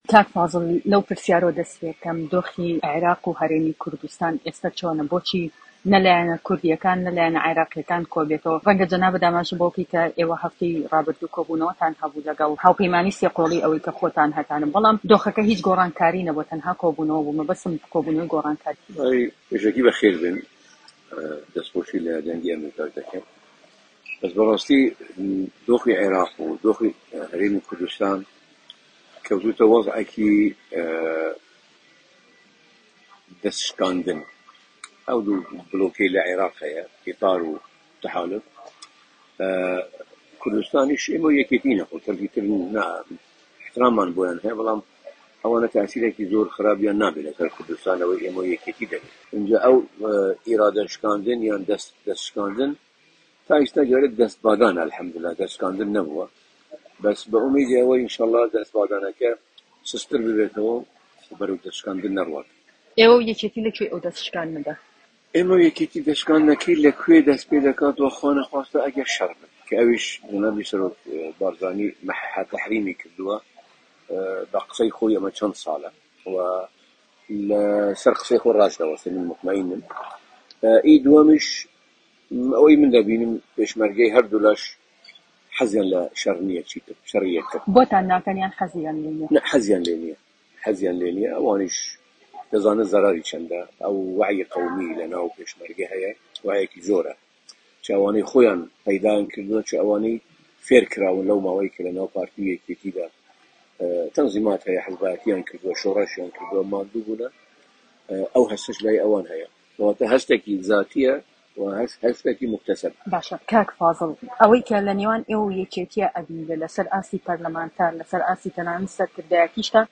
وتووێژکی ڕووبەڕوودا